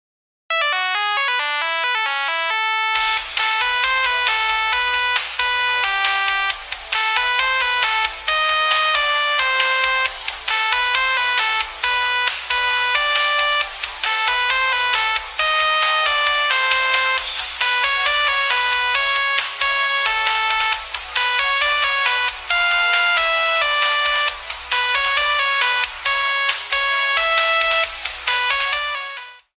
Nokia 3310